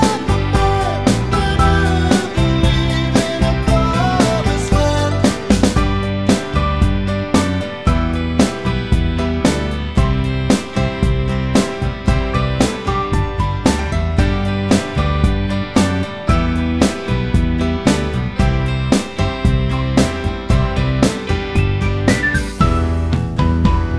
Minus Harmonica Rock 4:25 Buy £1.50